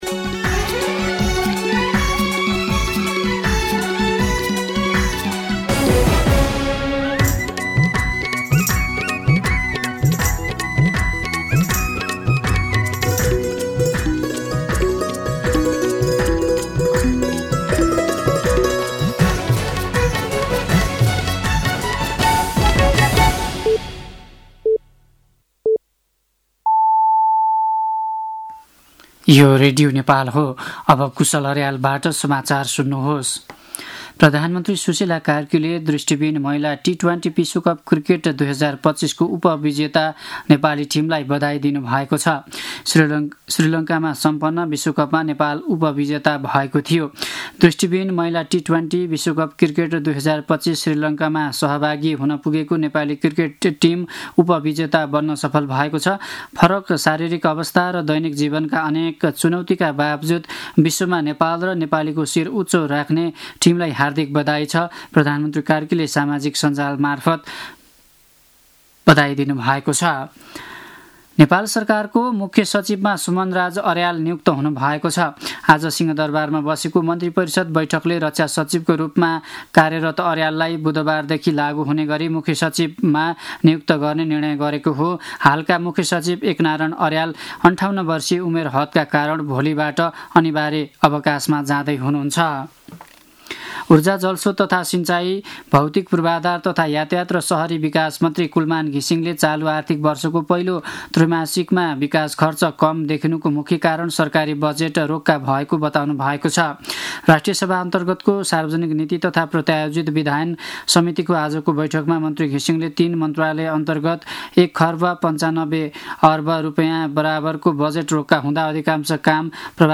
साँझ ५ बजेको नेपाली समाचार : ८ मंसिर , २०८२
5-pm-news-8-8.mp3